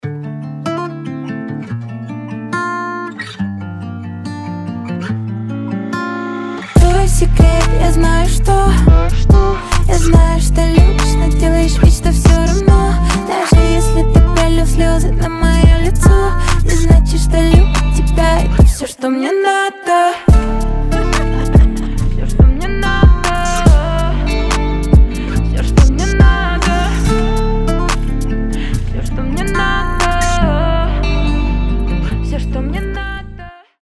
гитара
мелодичные
красивый женский голос
акустика
drill